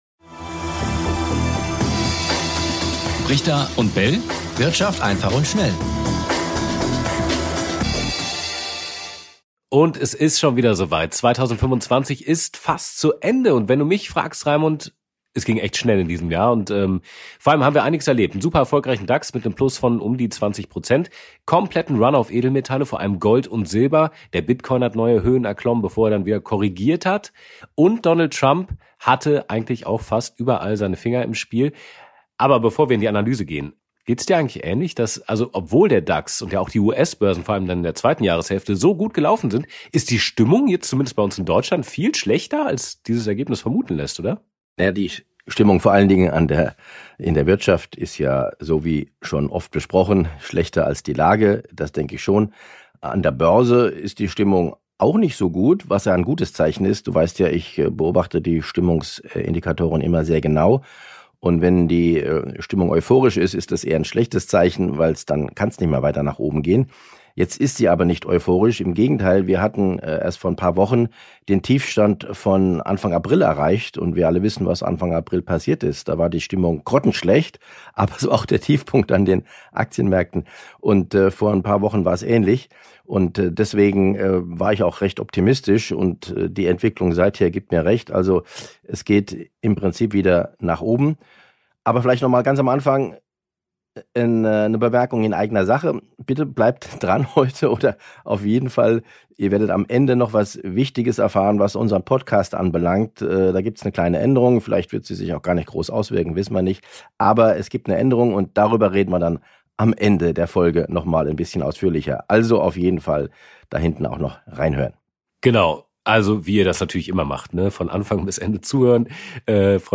Ein Gespräch über unterschätzte Risiken, extreme Kursziele und über die Frage, warum viele Anleger erst merken werden, was passiert, wenn es längst passiert ist.